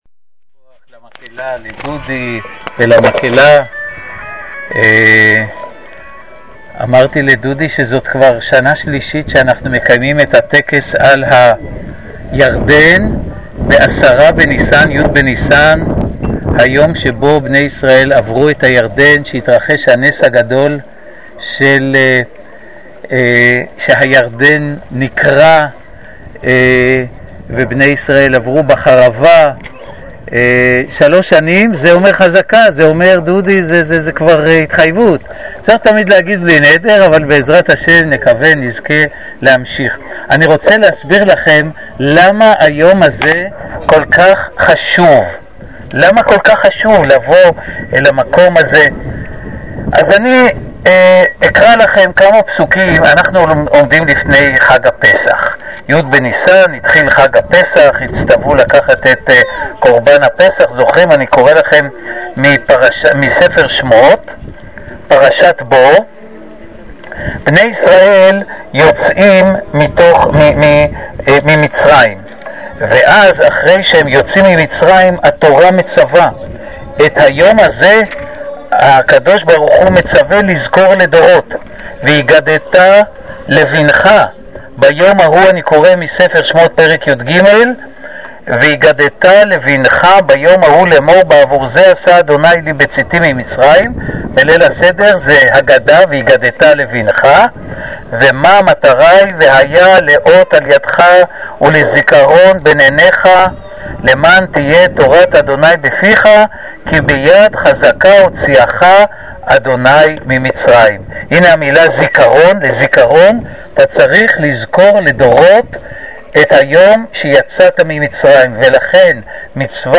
חוגגים את חציית הירדן - על גדת הירדן – בשיר ובשופרות